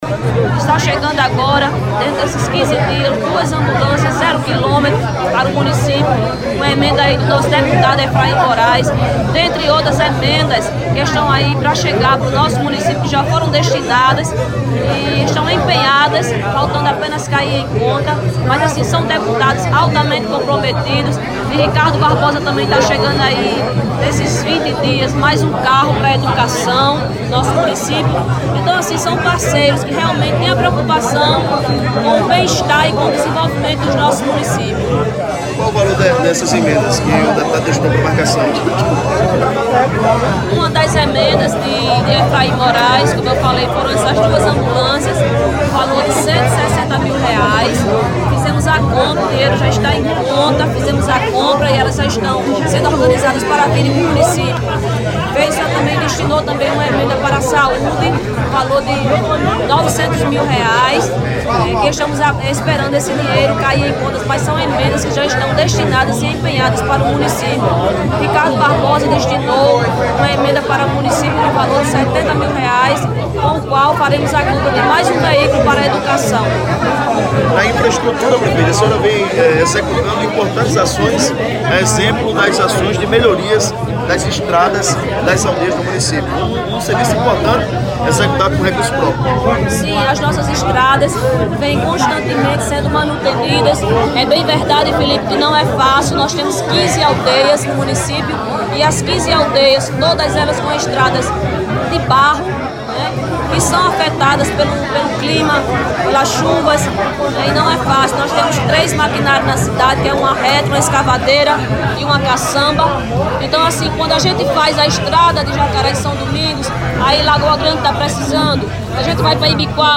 Durante as comemorações, Lili confirmou a reportagem do PBVale a aquisição dentro de 15 dias, de duas ambulâncias novas.
Ouça áudio 1 da prefeita Lili: